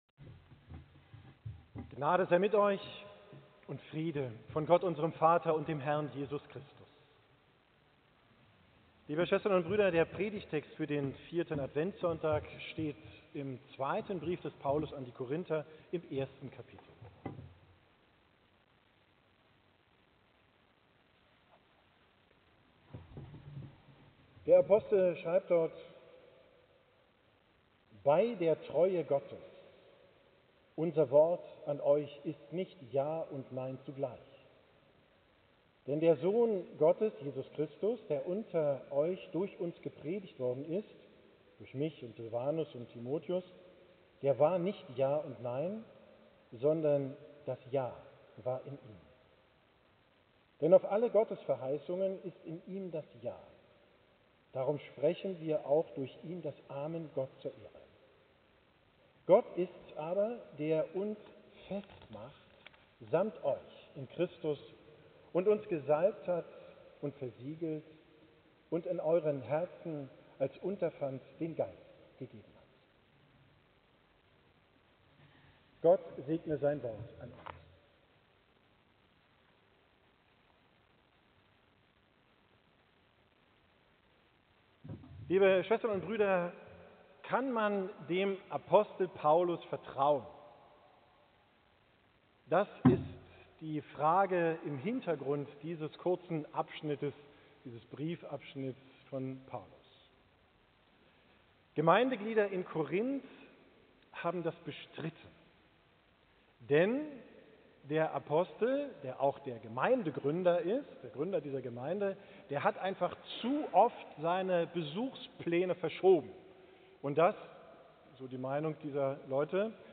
Predigt vom 4. Sonntag im Advent, 21. XII 2025